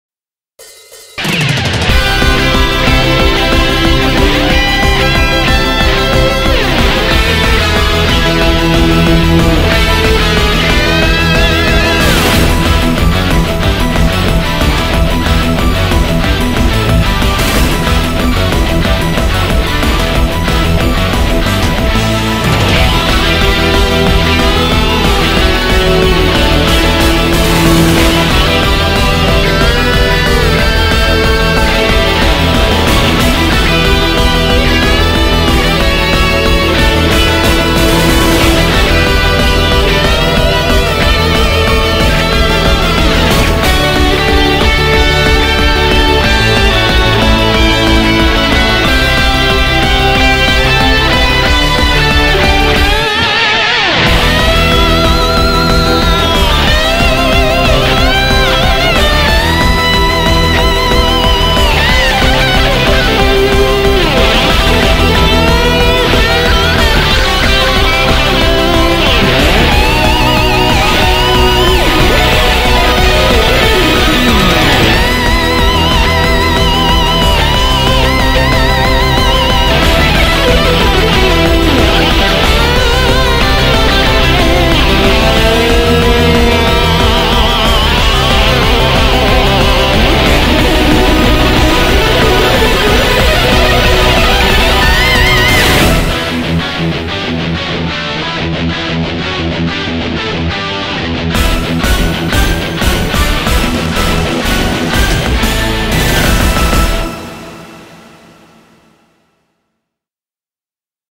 BPM184
Audio QualityPerfect (Low Quality)